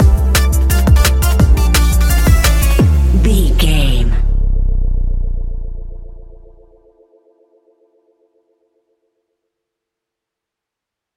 Ionian/Major
electronic
dance
techno
trance
synths
synthwave
instrumentals